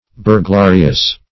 Burglarious \Bur*gla"ri*ous\, a.